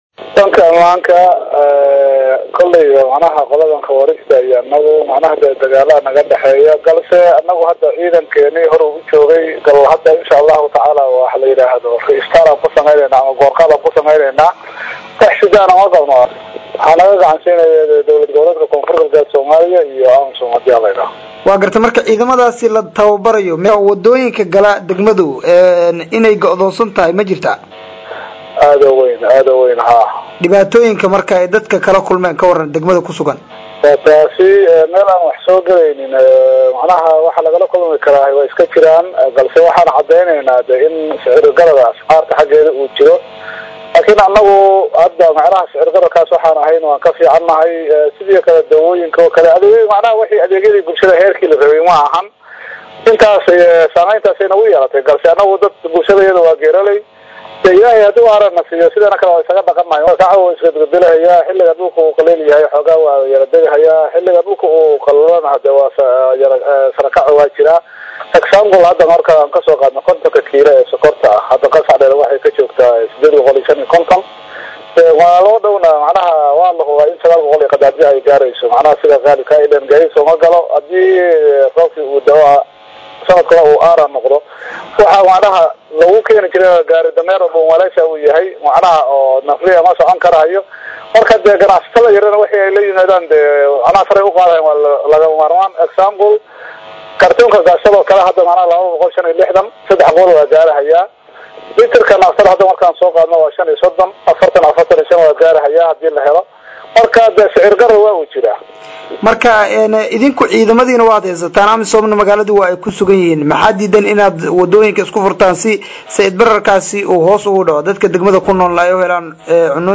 Sicirka-Raashinka-oo-sare-u-kacay-Qansaxdheere-dhageyso-Wareysi-Gudoomiyaha-Degmada-_-Mustaqbal-Radio.mp3